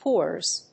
/pɔrz(米国英語), pɔ:rz(英国英語)/